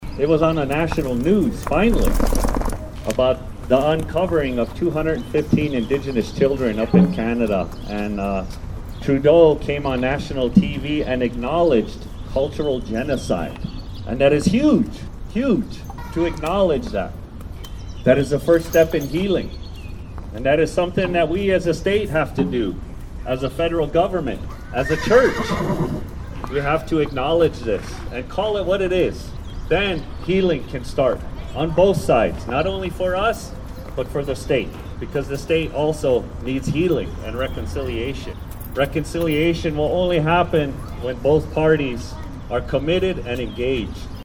Raising awareness about Missing and Murdered Indigenous Relatives (MMIR) was the goal of a gathering on the Capitol Building lawn this morning (June 4, 2021) in Pierre.
One horse in the group had no visible rider, but Crow Creek tribal chairman Peter Lenkeek says it was carrying a heavy load.